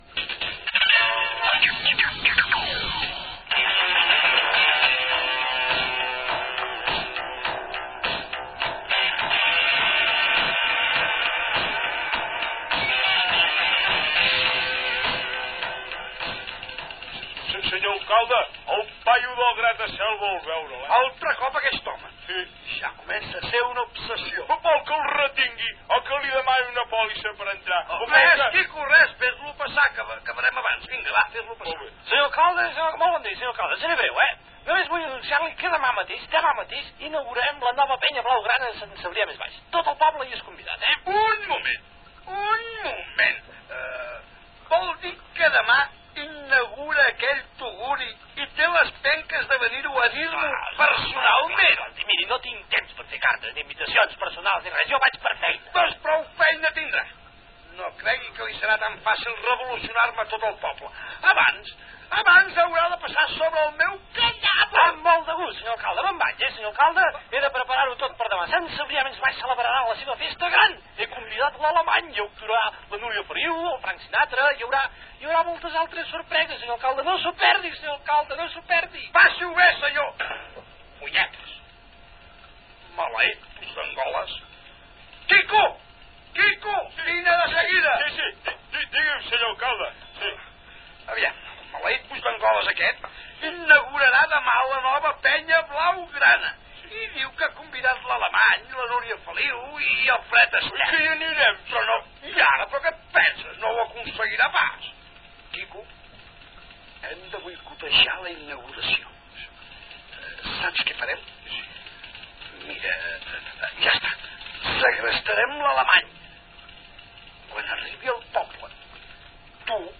Radionovel·la «La masia o aquest any, sí!»
Ficció